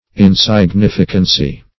Insignificancy \In`sig*nif"i*can*cy\, n.